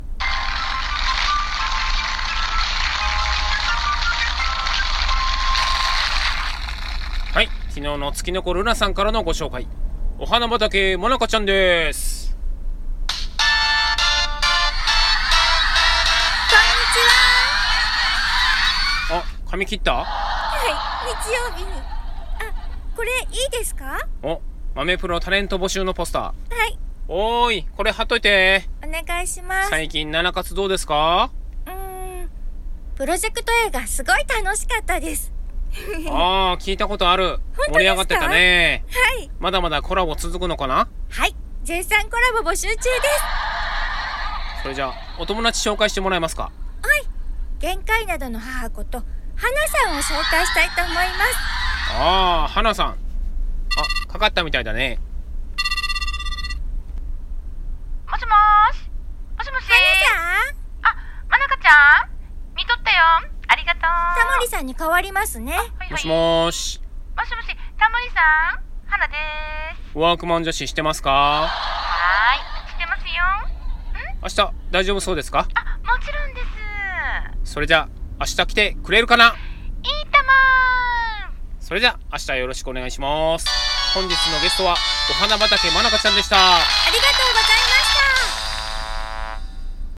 コラボ用三人声劇「テレホンショッキング」